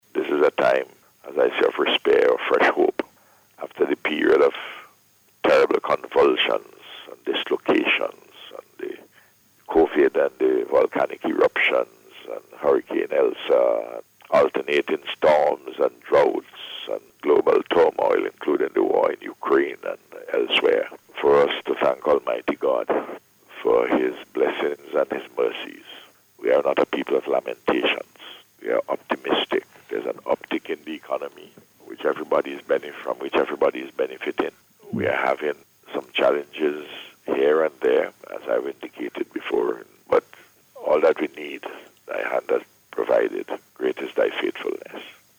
Dr. Gonsalves made the statement while speaking on NBC Radio’s Face to Face program this week where he provided updates on matters of national importance.